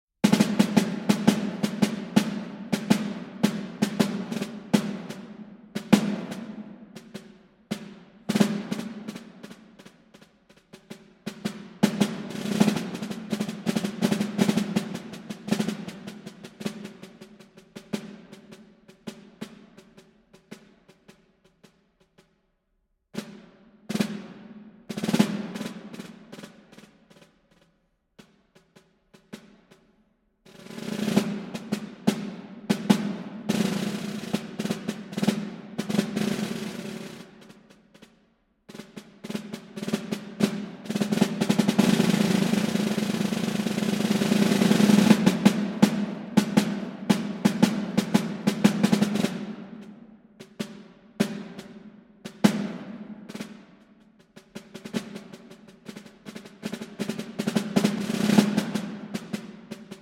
Snare Drum